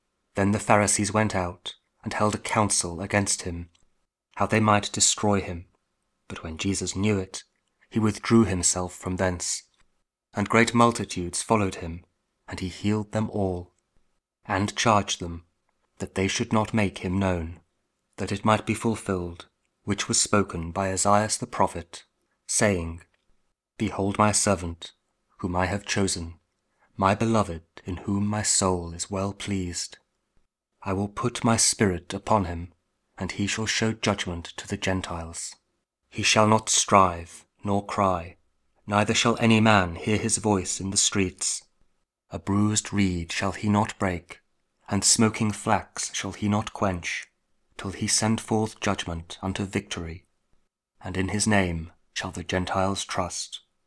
Mathew 12: 14-21 – Week 15 Ordinary Time, Saturday (King James Audio Bible KJV, Spoken Word)